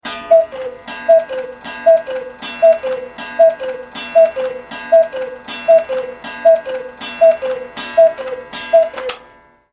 Clock Sound Effect Free Download
Clock